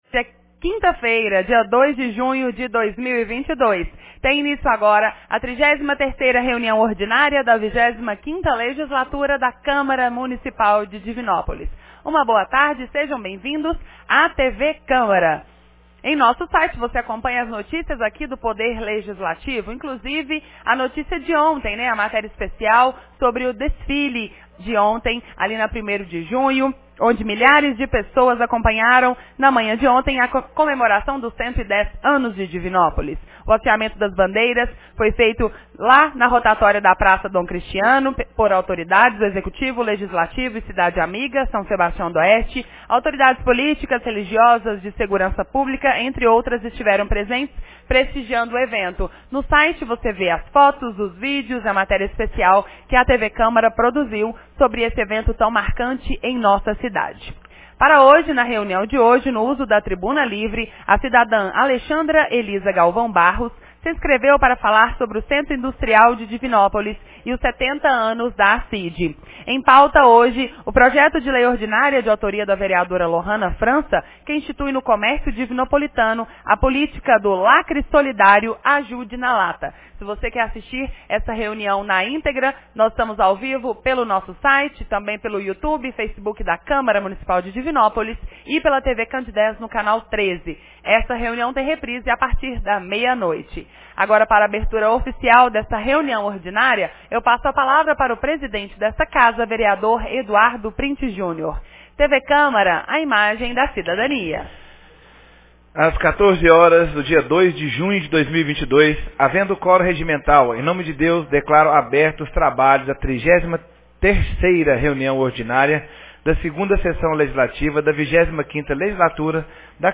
33ª Reunião Ordinária 02 de junho de 2022